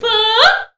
CLASSIC.wav